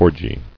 [or·gy]